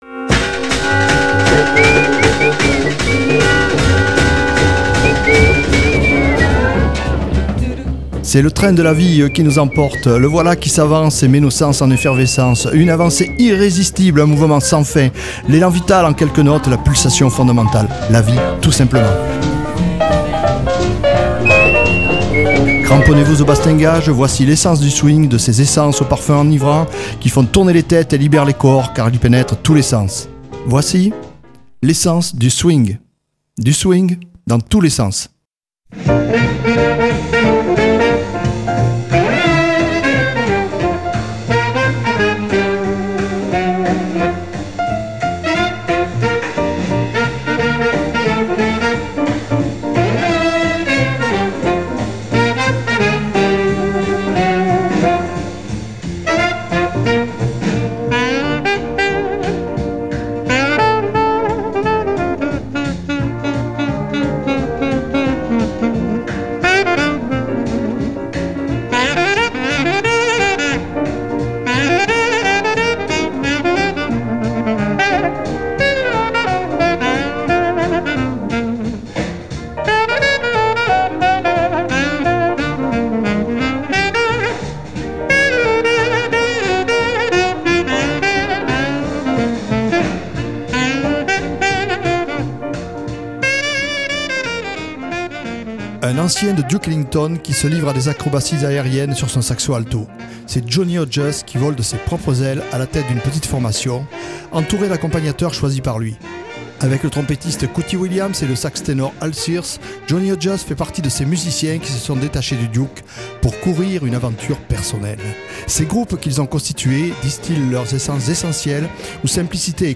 Leur montée en puissance repose sur un swing intense qui assoit solidement une cohésion d’ensemble favorable a? la libre expression des solistes.